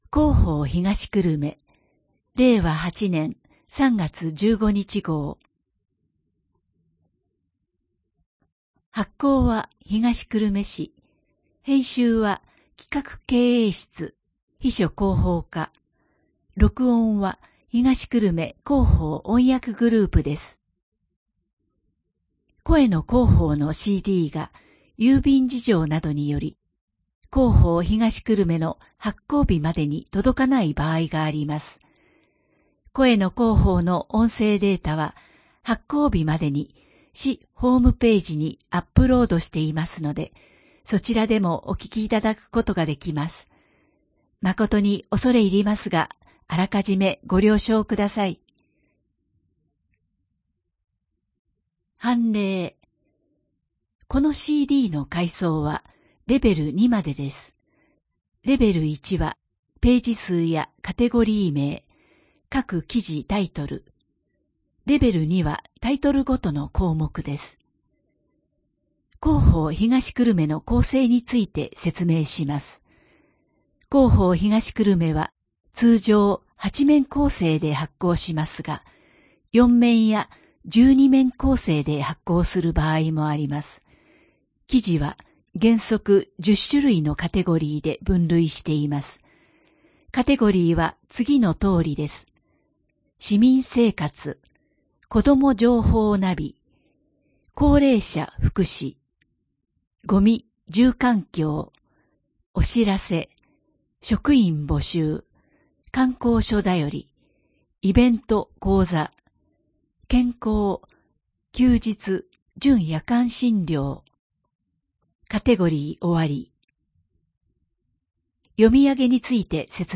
声の広報（令和8年3月15日号）